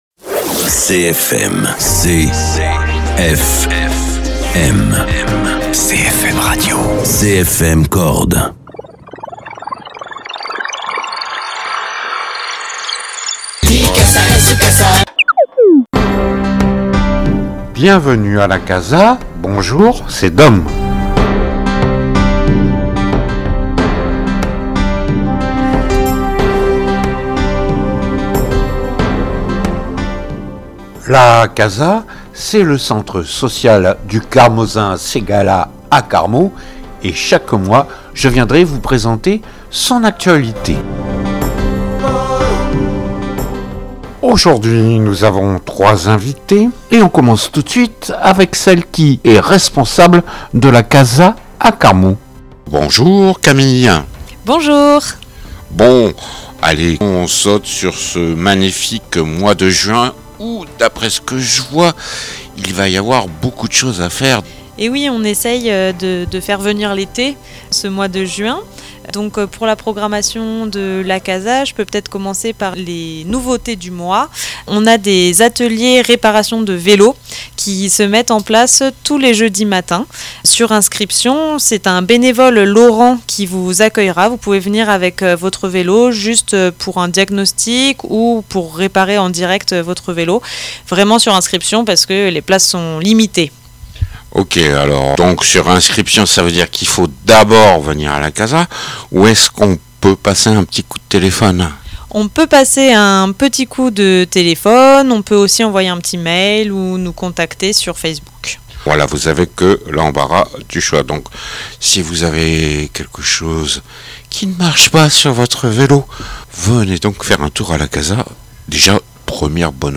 Tour d’horizon des actualités du mois de juin de la CASA. Il est également question ce mois-ci de la santé sur le Carmausin Ségala et des dispositifs développés sur l’intercommunalité. Enfin, un usager régulier de la CASA nous parle de son parcours et de sa fréquentation du centre social.